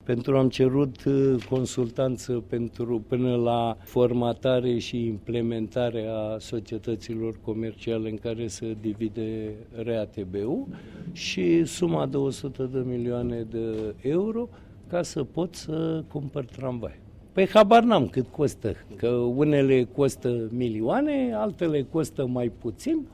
Primarul Capitalei, Sorin Oprescu: